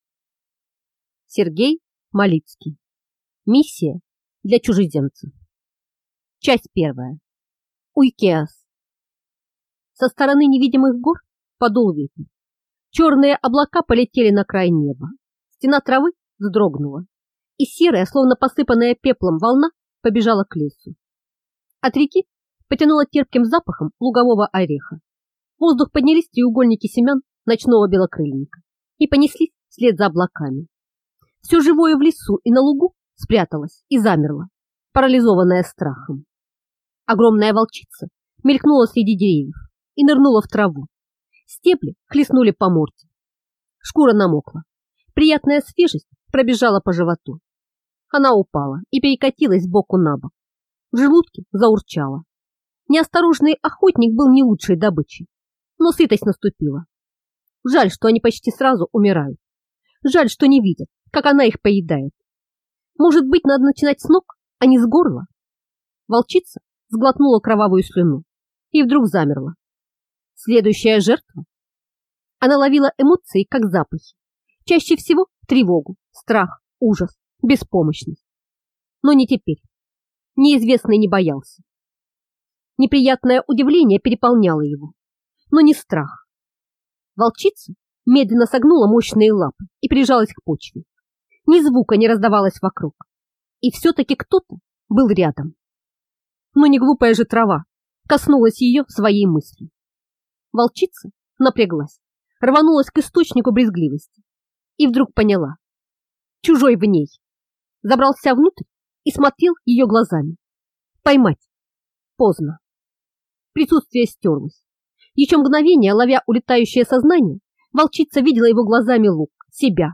Аудиокнига Миссия для чужеземца | Библиотека аудиокниг